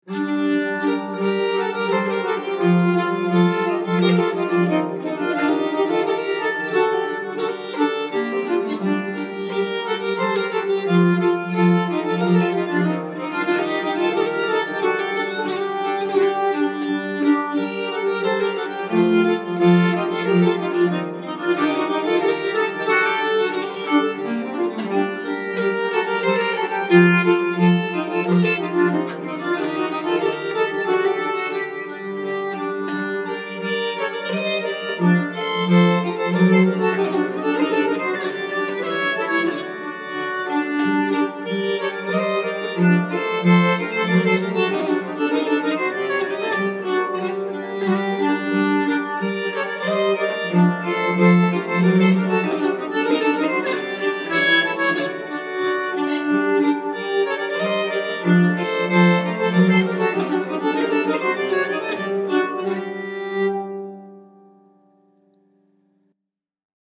Méthode de nyckelharpa : Les Livrets
stensele polska.mp3